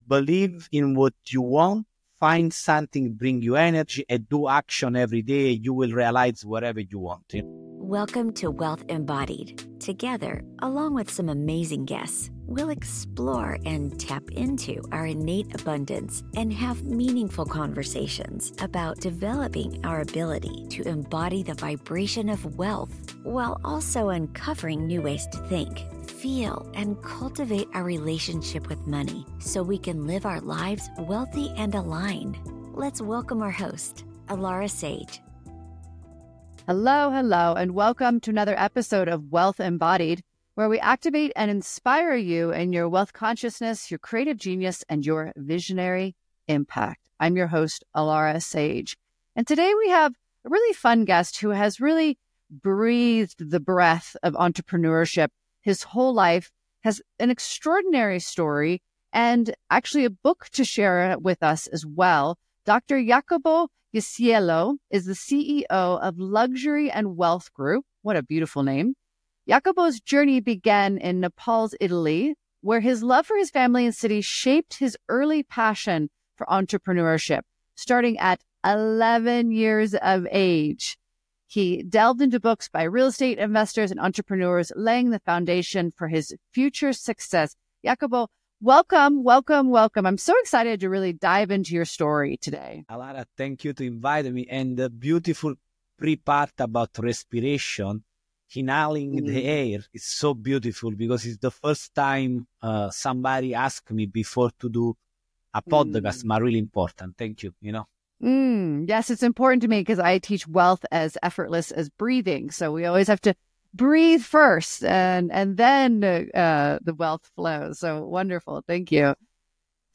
Conversations and guest interviews to help you learn how to activate wealth consciousness, embody the frequency of wealth and apply financial strategies to your life. Listen to compelling conversations and insights on Quantum Mechanics, the Law of Attraction, Manifestation, and Wealth Creation and Management.